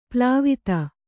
♪ plāvita